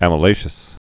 (ămə-lāshəs)